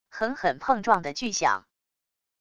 狠狠碰撞的巨响wav音频